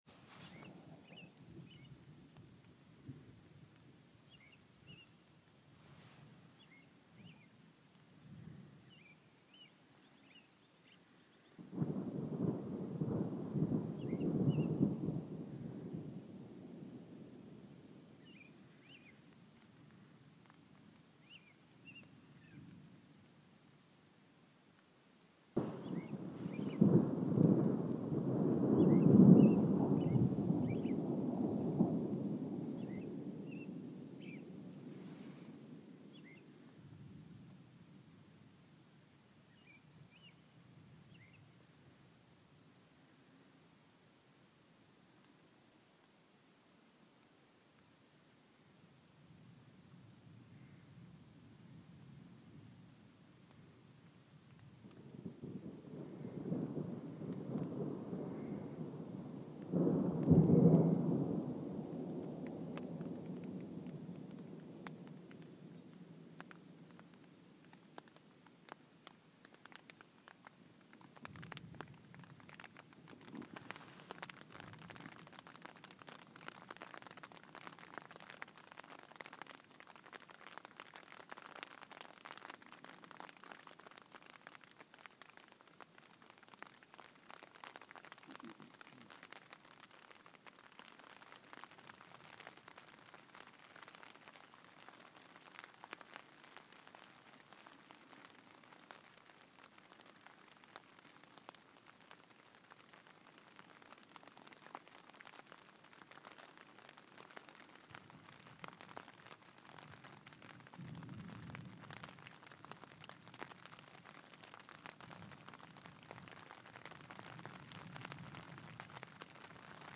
lake with rain
Forced quiet slowly becomes right – soul washed by the tapping of rain upon my tent; elemental rest.
thunder-2.m4a